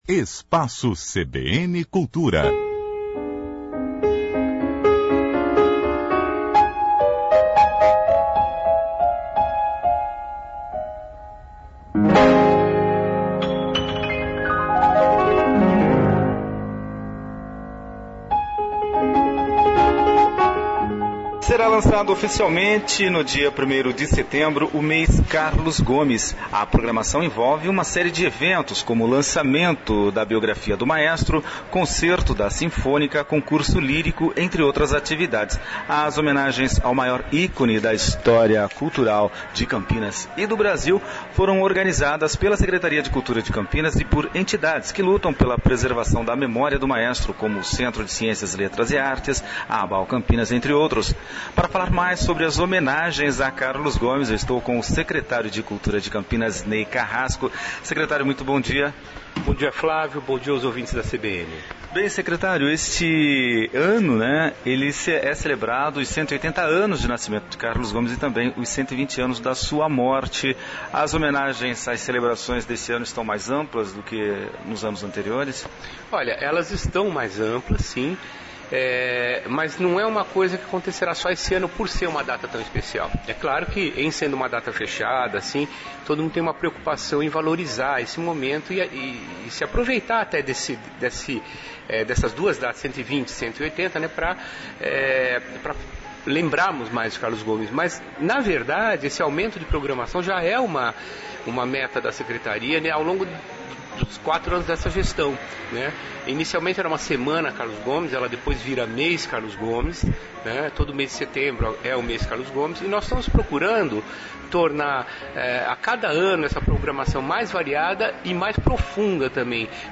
Secretário de Cultura,Ney Carrasco fala sobre o Mês de Carlos Gomes